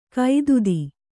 ♪ kaidudi